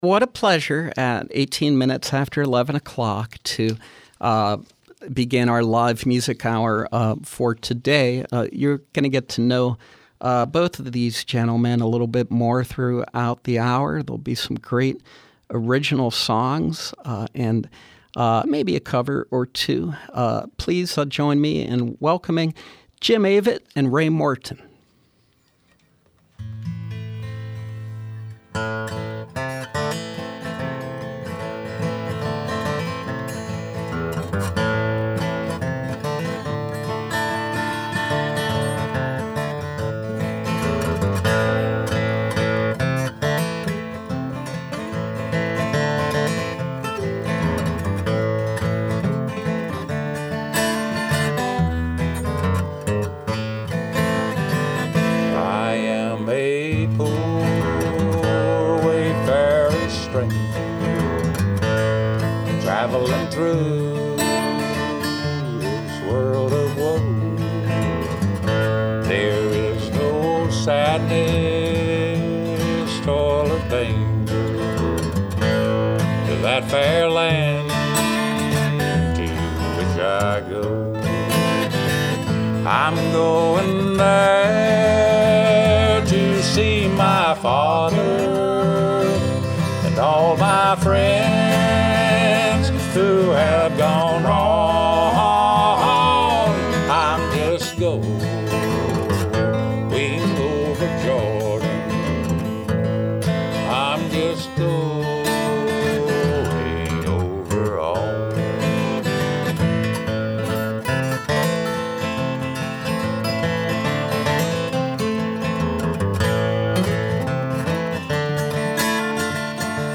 Country singer
guitarist
perform live